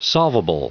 Prononciation du mot solvable en anglais (fichier audio)
Prononciation du mot : solvable